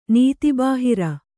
♪ nīti bāhira